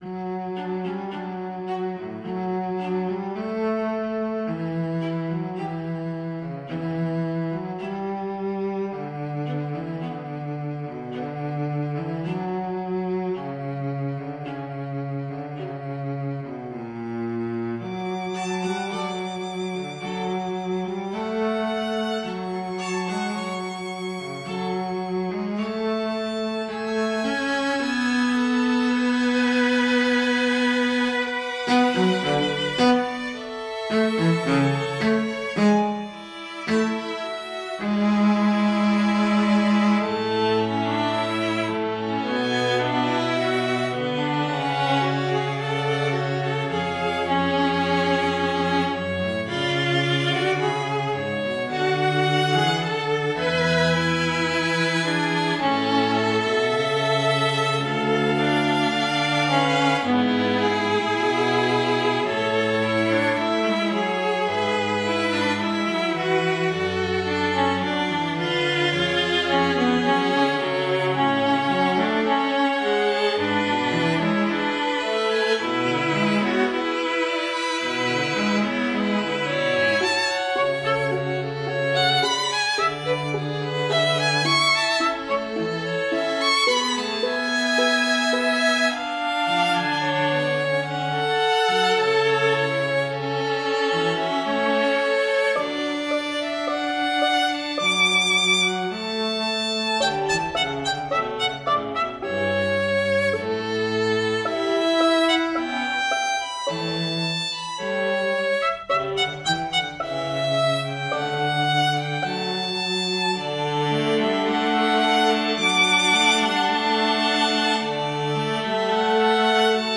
Hi 🙂 Here's the third and last movement of my first complete string quartet. It's a moderato which starts quietly in the main D Major tone, before modulating in its first theme to a strong A Major viola-leaded melody.